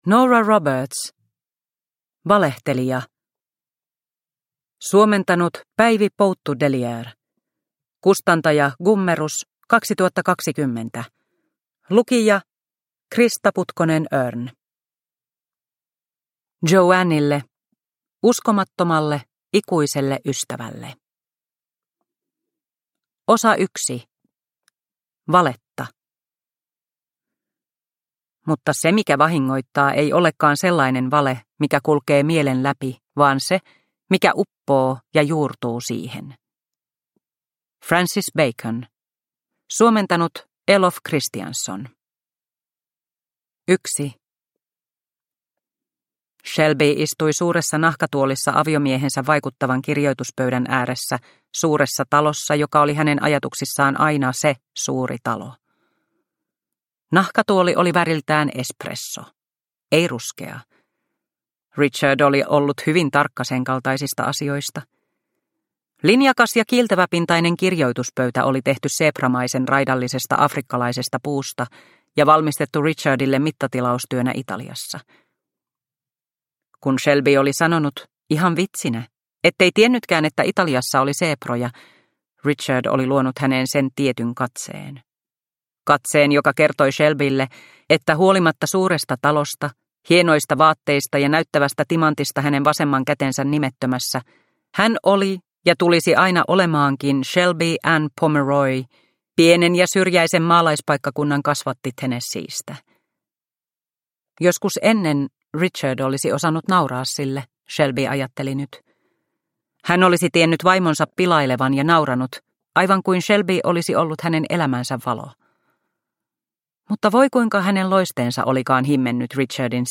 Valehtelija – Ljudbok – Laddas ner